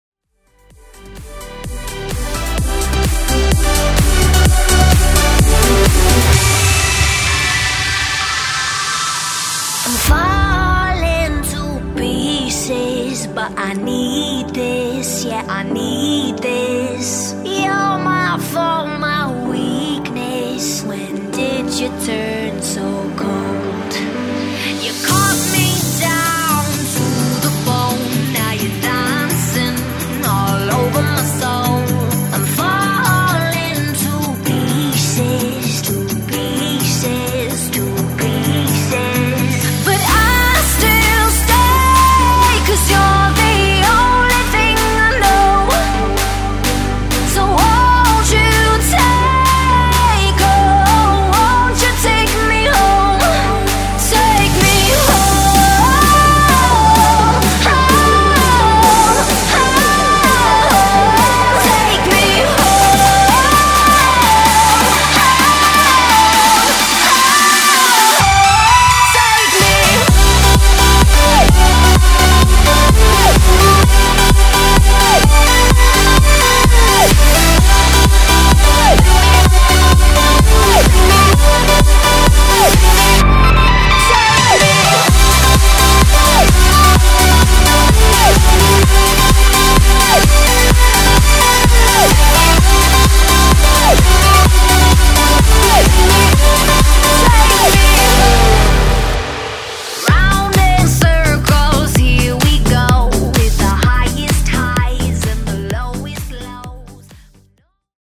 Genre: FUTURE HOUSE
Clean BPM: 124 Time